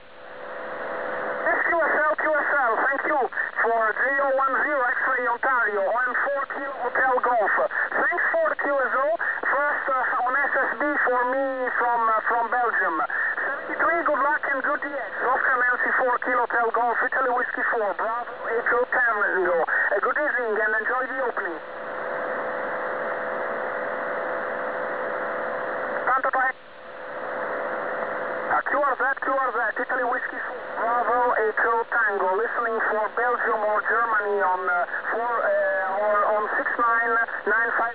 Here are some sound clips of signals received summer 2010 on 70mhz.